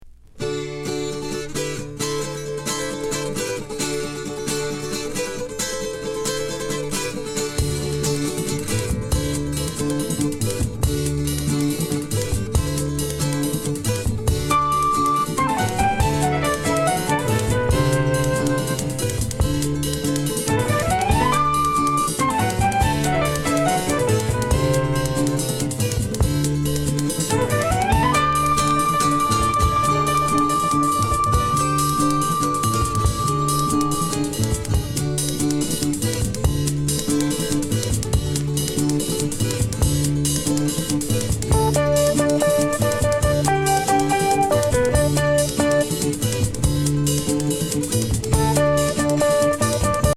MONO盤。